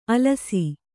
♪ alasi